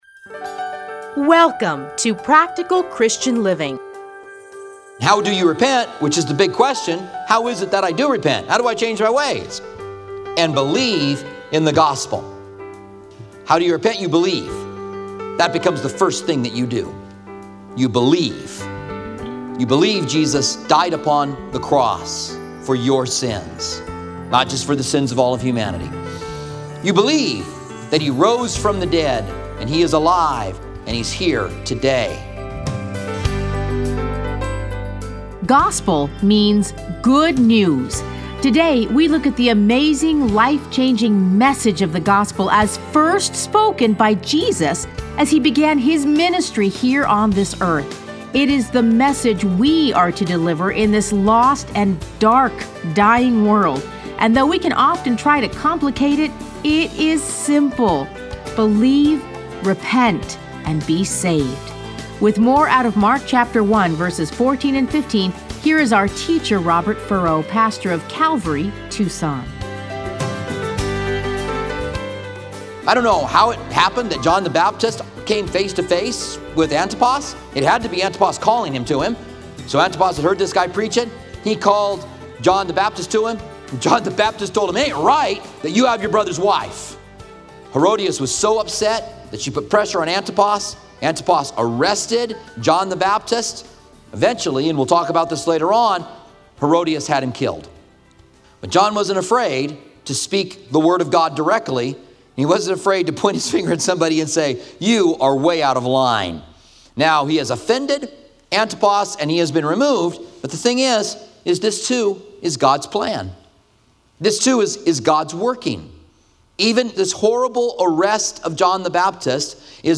Listen to a teaching from Mark 1:14-15.